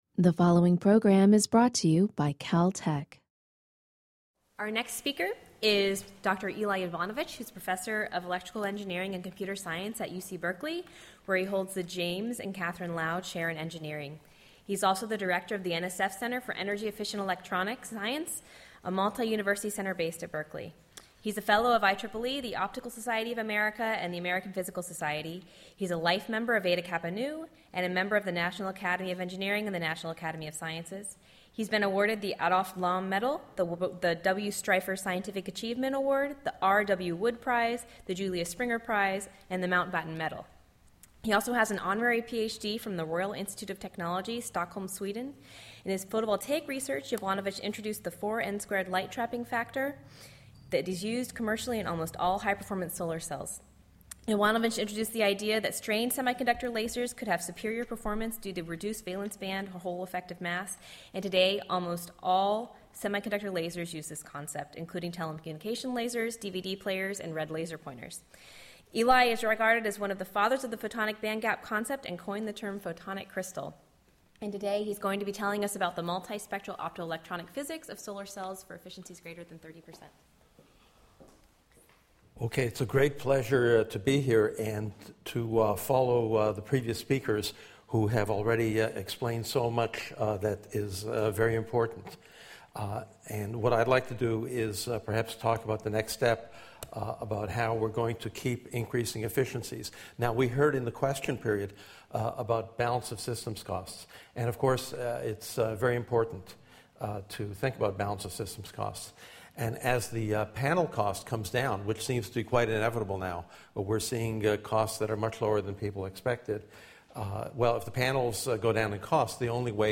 Hameetman Auditorium at the Cahill Center [map]